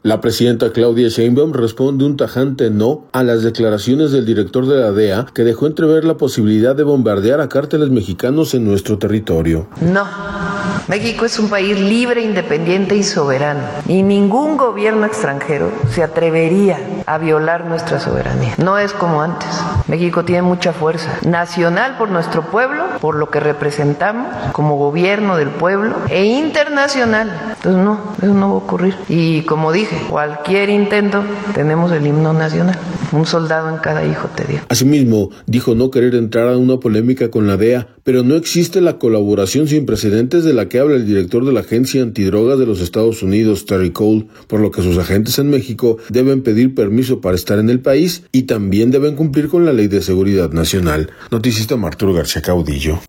La presidenta Claudia Sheinbaum responde un tajante ‘no’ a las declaraciones del director de la DEA, que dejó entrever la posibilidad de bombardear a cárteles mexicanos en nuestro territorio.